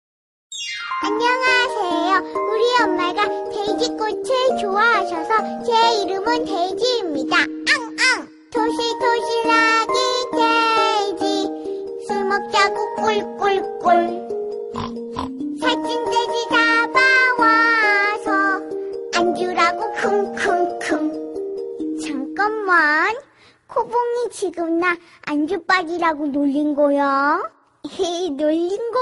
Genre: Nada dering Korea